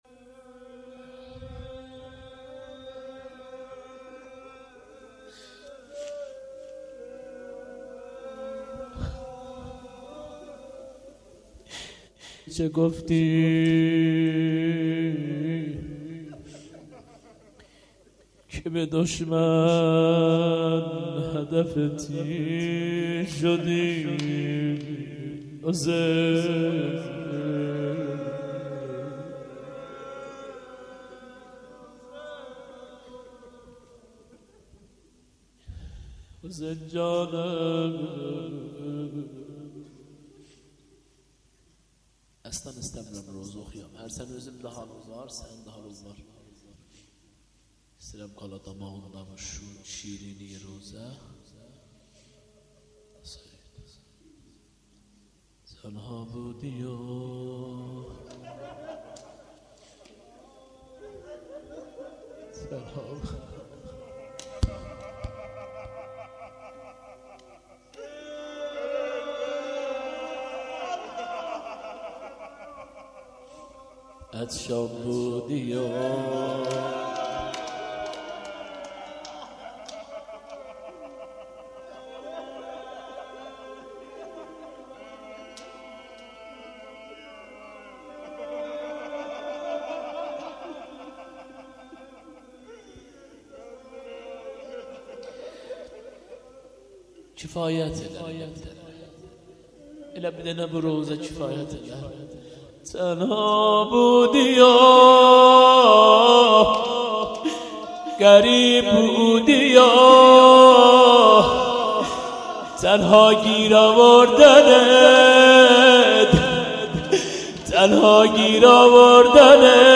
روضه و روایت